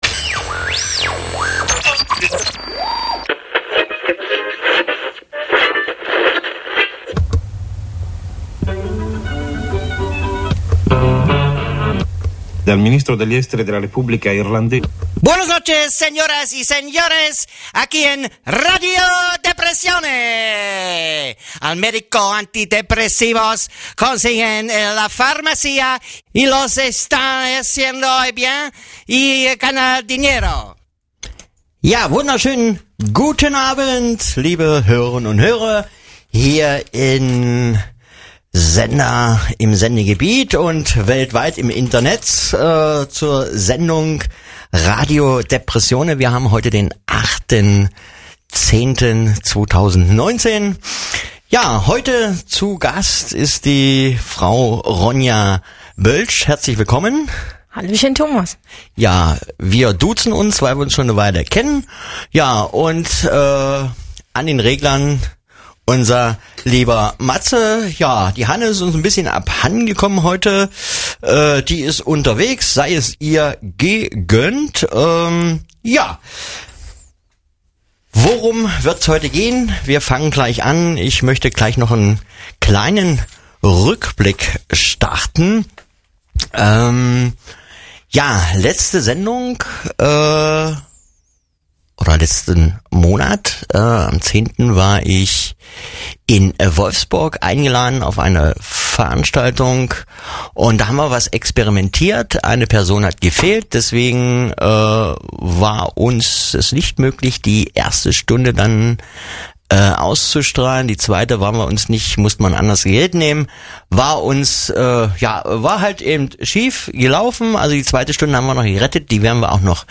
Es geht um psychische Störungen und Erkrankungen, Behandlungsmöglichkeiten und Anlaufpunkte für Betroffene. Dazu gibt es regelmäßig Interviews mit Fachleuten und Betroffenen, Buchtipps und Umfragen zu bestimmten Themen.